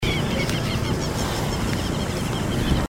Quero-quero (Vanellus chilensis)
Nome em Inglês: Southern Lapwing
Fase da vida: Adulto
Localidade ou área protegida: Reserva Ecológica Costanera Sur (RECS)
Condição: Selvagem